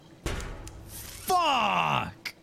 Play, download and share *CLANK* FUCK 2.0 original sound button!!!!
clank-fuck-2.mp3